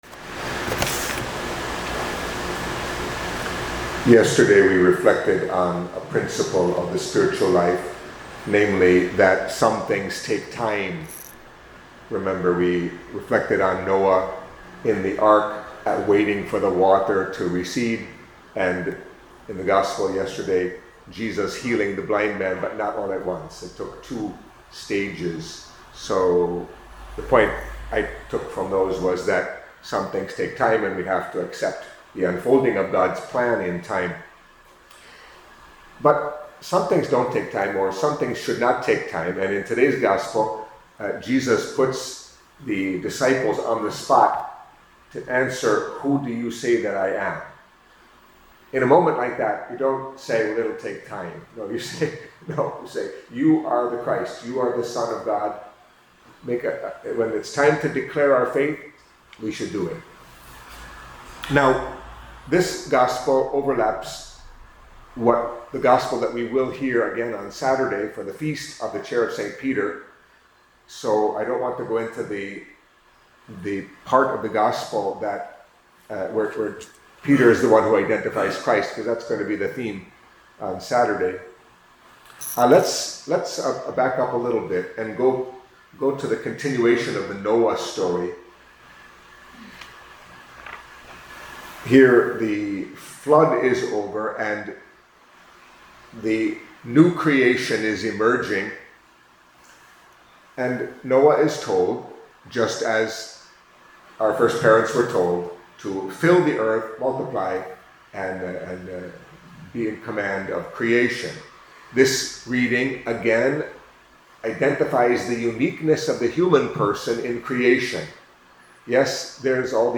Catholic Mass homily for Thursday of the Sixth Week in Ordinary Time